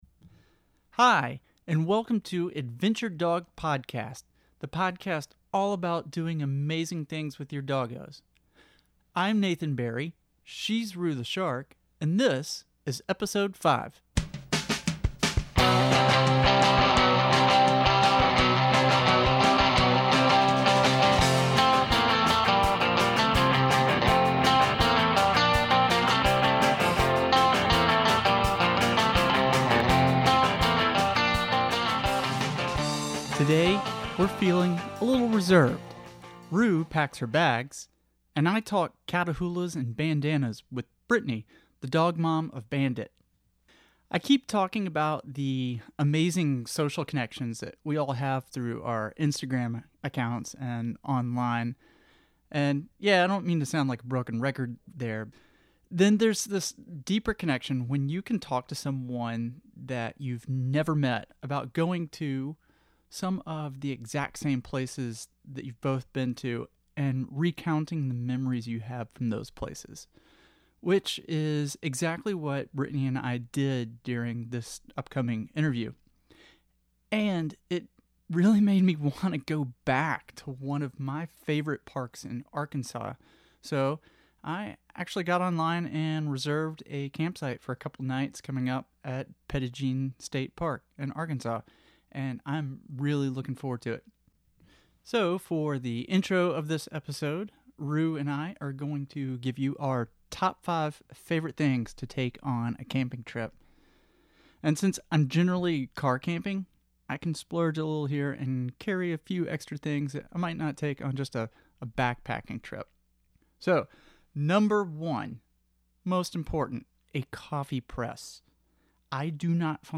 Today’s interview